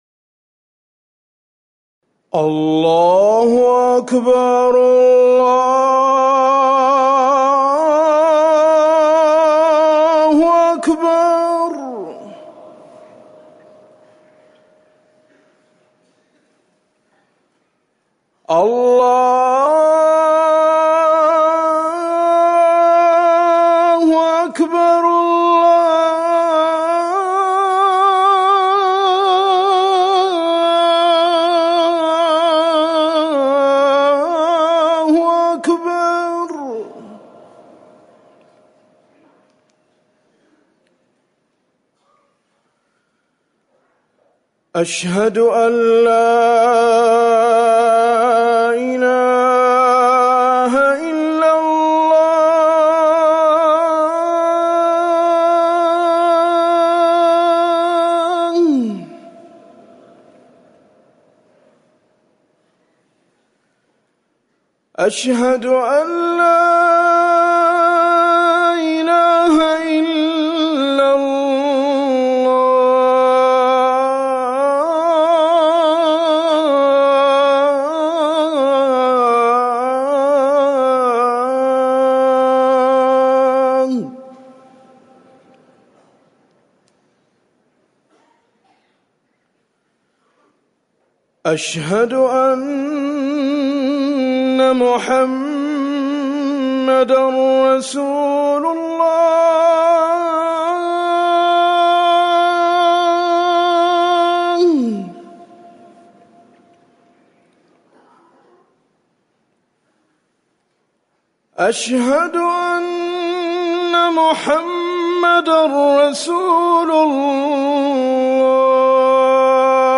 أذان الفجر الأول - الموقع الرسمي لرئاسة الشؤون الدينية بالمسجد النبوي والمسجد الحرام
تاريخ النشر ٢١ صفر ١٤٤١ هـ المكان: المسجد النبوي الشيخ